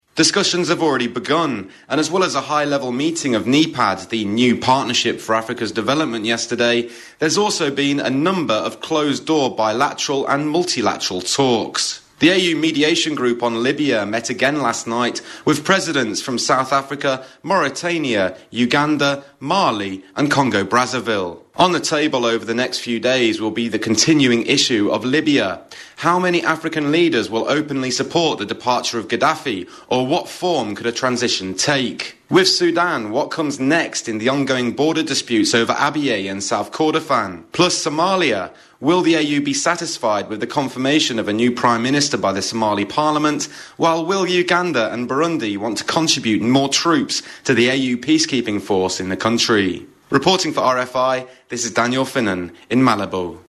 Report: The beginning of talks, 30th June 2011